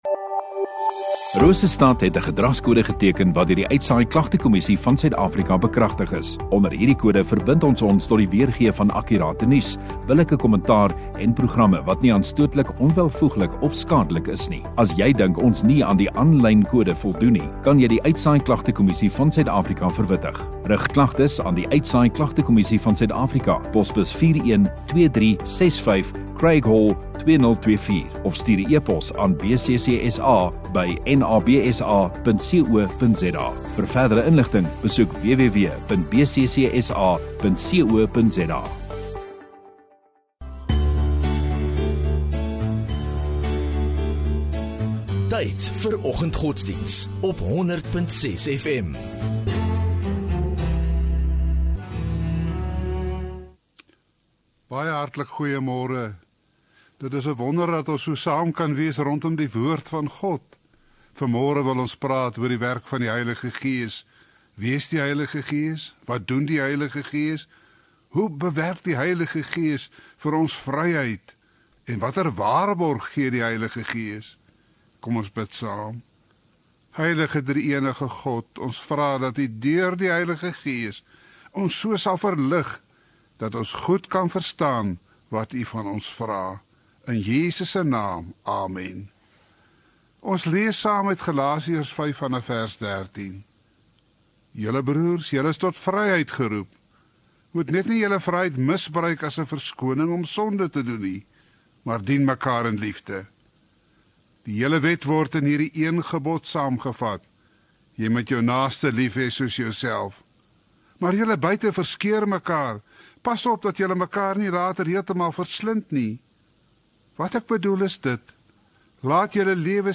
17 Sep Woensdag Oggenddiens